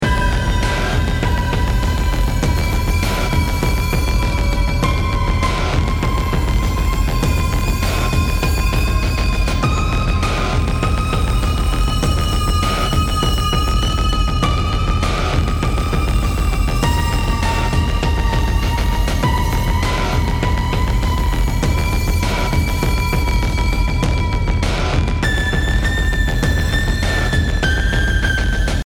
528hz BPM100-109 Game Instrument Soundtrack インストルメント
BPM 100